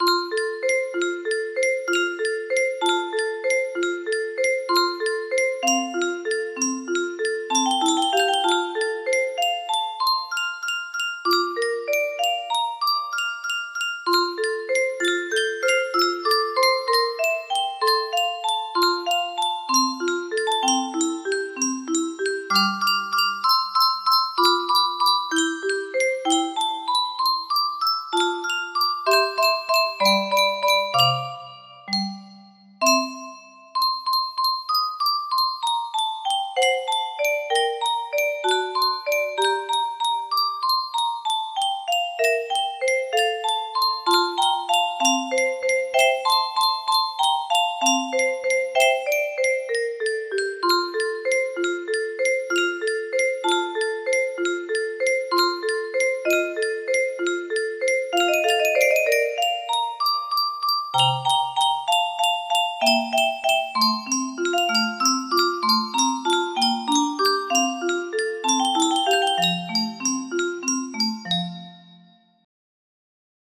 Muzio Clementi - Sonatina Op. 36 No. 1 2nd Movement music box melody
Full range 60